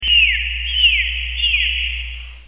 دانلود صدای دلفین 9 از ساعد نیوز با لینک مستقیم و کیفیت بالا
جلوه های صوتی